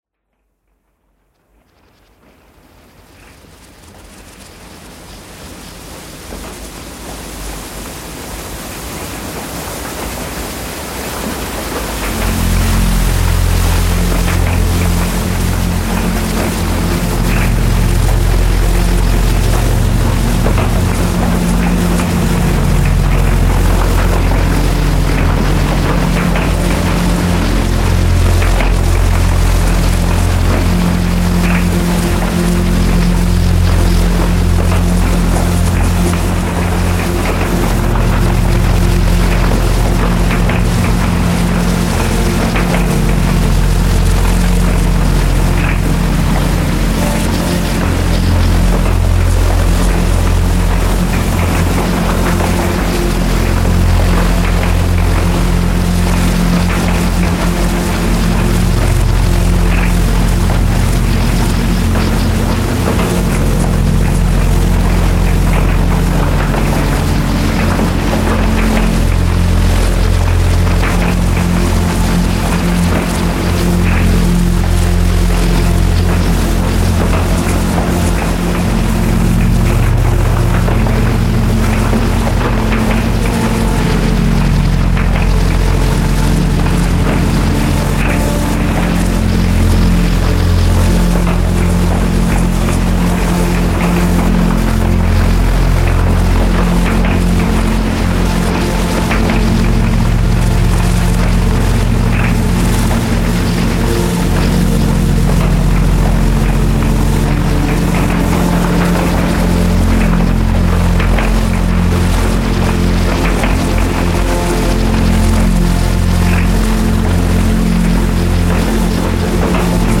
Some rain and a Subharmonicon